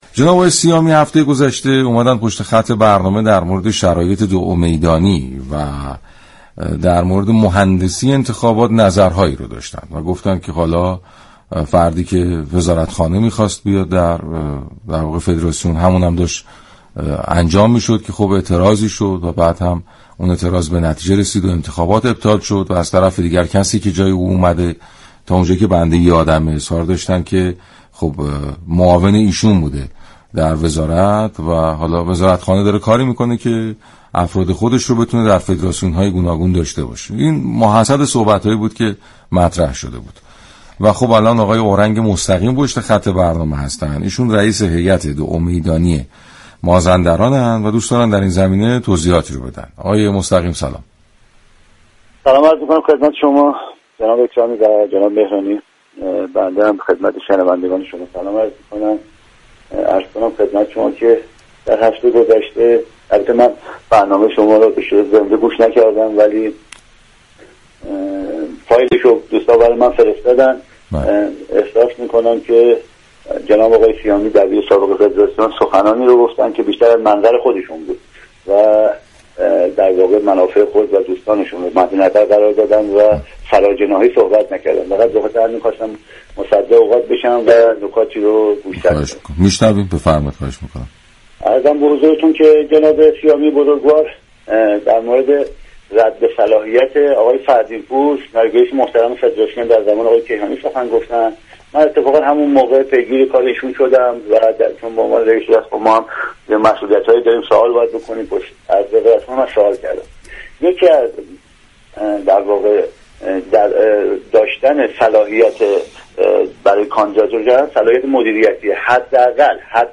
شما مخاطب محترم می توانید از طریق فایل صوتی پیوست شنونده این گفتگو باشید.